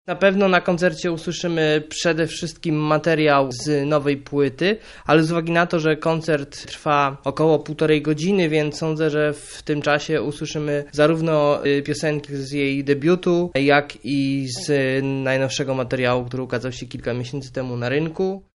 zapowiedź-Rusowicz.mp3.mp3